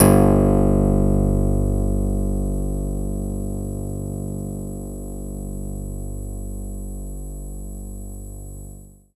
66 BASS   -L.wav